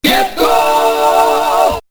Get goal!" has been changed, and now matches the final version.
vrp get goal 2.mp3